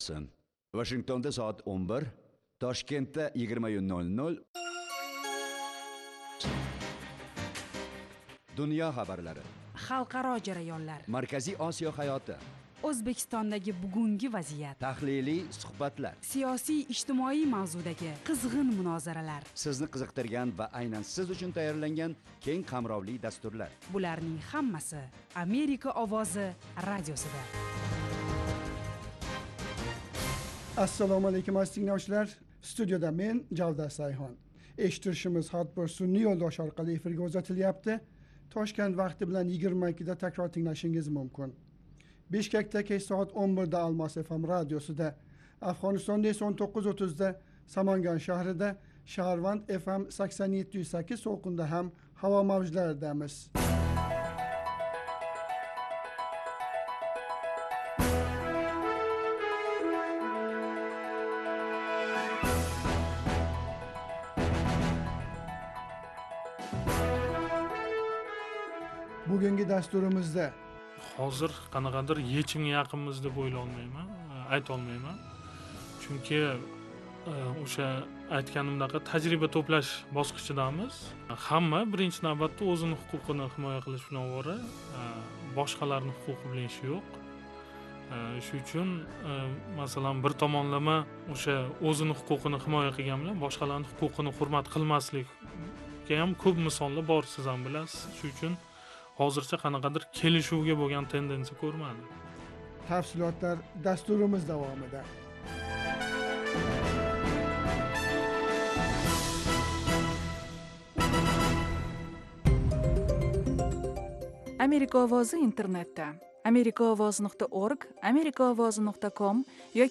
Toshkent vaqti bilan har kuni 20:00 da efirga uzatiladigan 30 daqiqali radio dastur kunning dolzarb mavzularini yoritadi. Xalqaro hayot, O'zbekiston va butun Markaziy Osiyodagi muhim o'zgarishlarni, shuningdek, AQSh bilan aloqalarni tahlil qiladi.